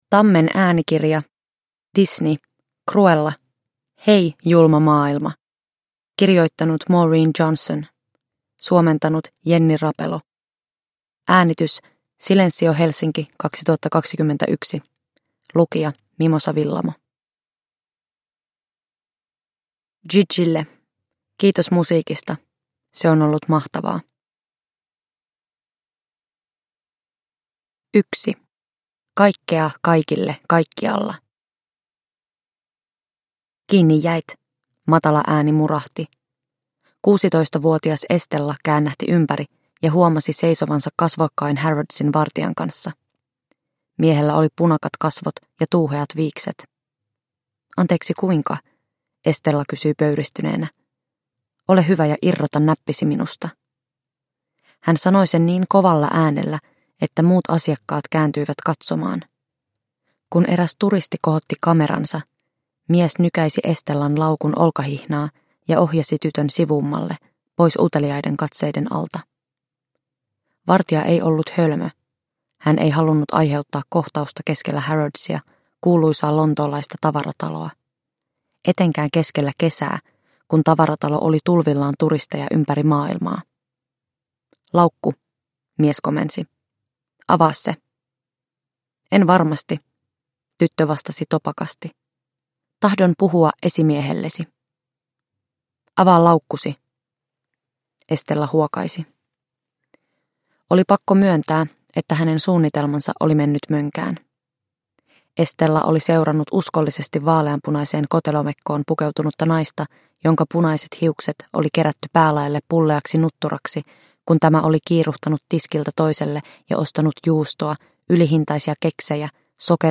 Disney. Cruella. Hei, julma maailma – Ljudbok – Laddas ner